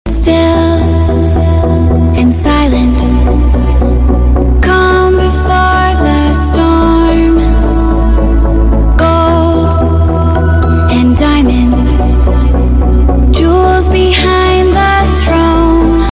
The ethereal classic sound of sound effects free download
The ethereal classic sound of nature sounds good, the ears are pregnant